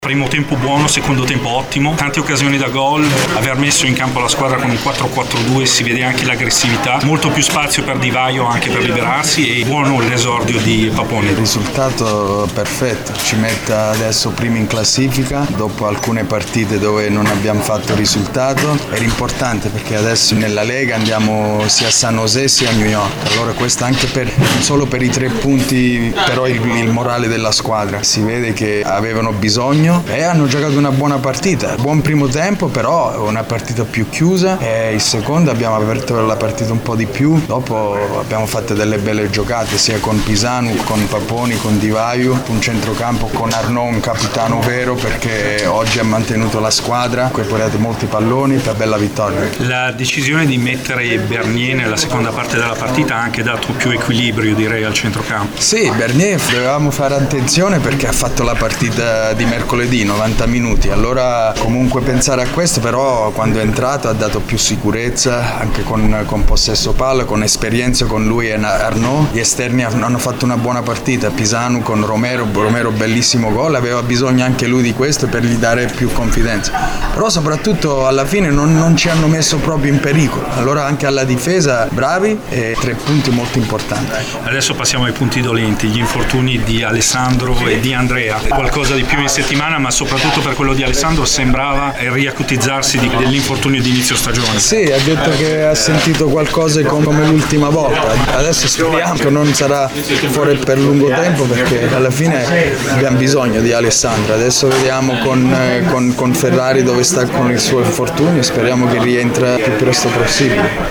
Servizio completo e interviste